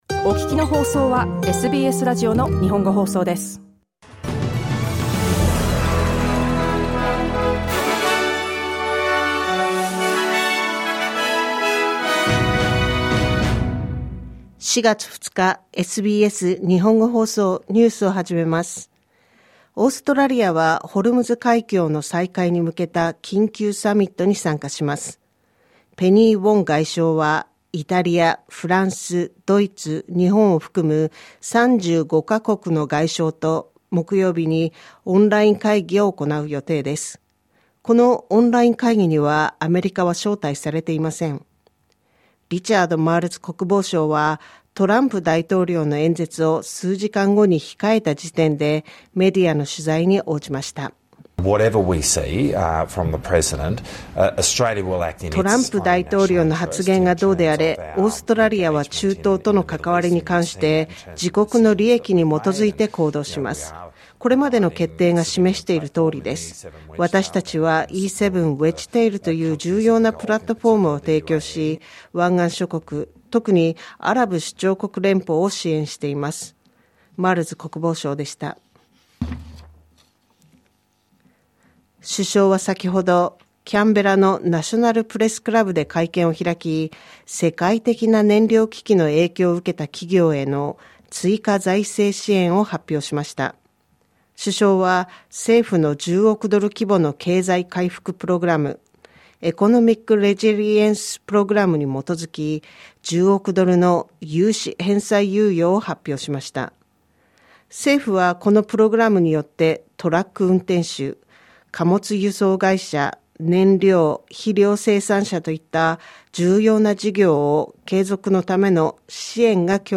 News from today's live program (1-2pm).